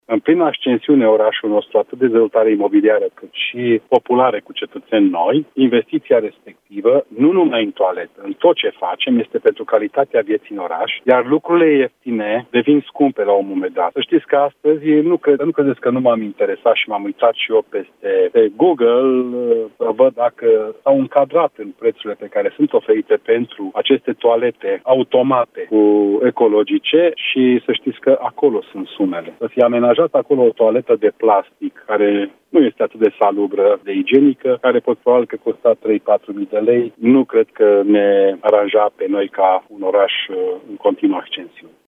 „Nu cumpărăm lucruri ieftine, de proastă calitate”, a spus primarul orașului Ghimbav, Ionel Fliundra, la Europa FM.
Primarul orașului Ghimbav, Ionel Fliundra: „Este pentru calitatea vieții în oraș”